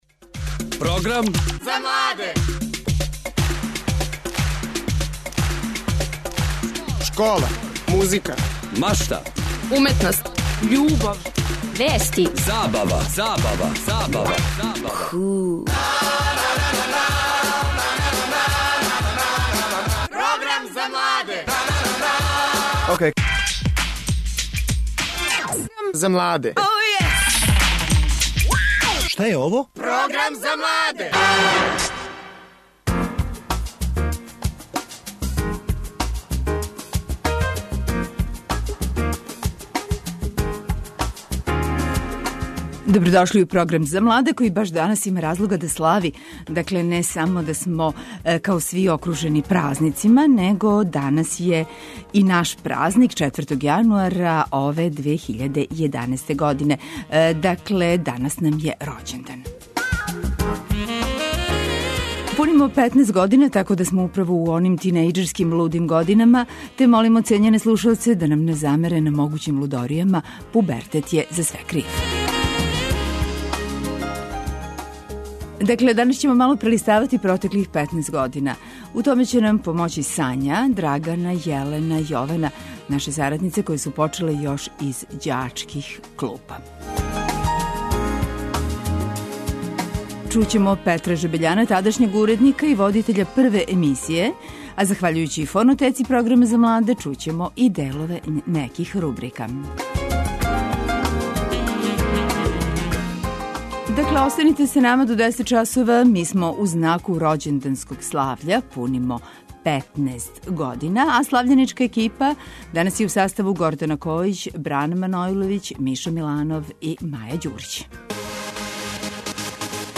Разговараћемо са гостима који су некад били сарадници нашег програма. Такође, имаћете прилику да чујете неке од најзанимљивијих делова појединих емисија које су емитоване у протеклим годинама.